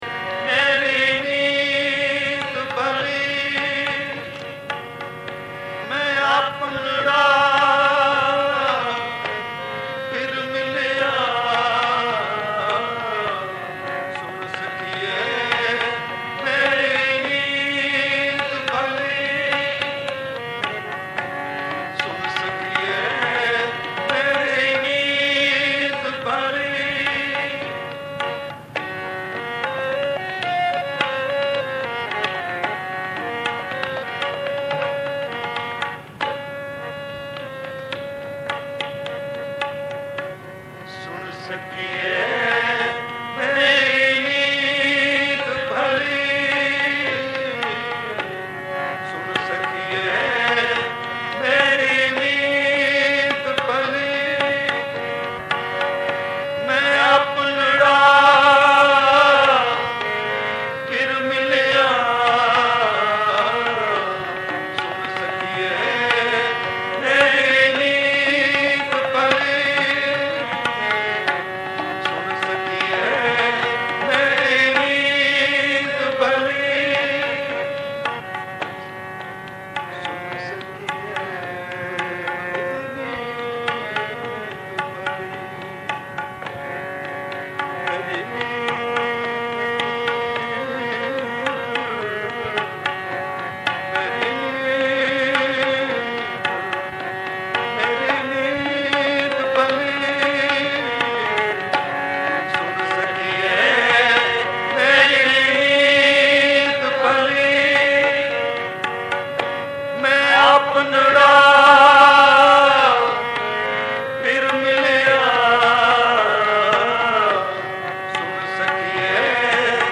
Raag Gauri Chhant Guru Arjan Dev
Kirtan